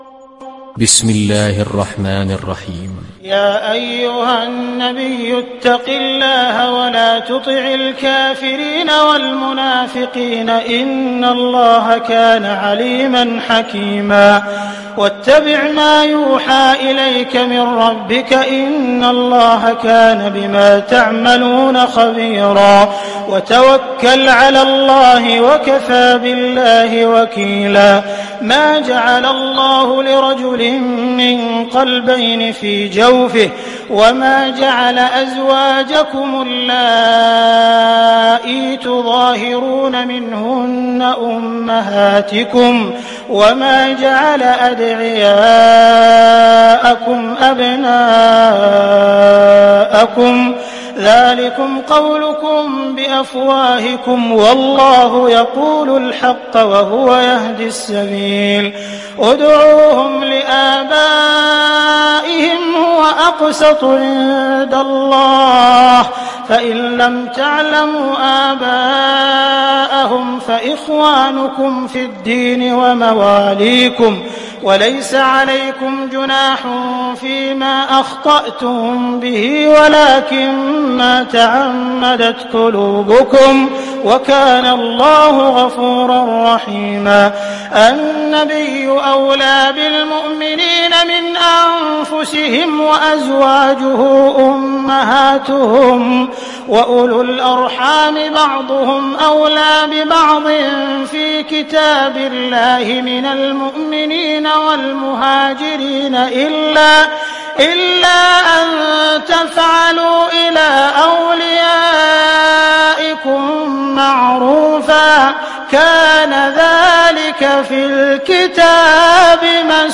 دانلود سوره الأحزاب mp3 عبد الرحمن السديس روایت حفص از عاصم, قرآن را دانلود کنید و گوش کن mp3 ، لینک مستقیم کامل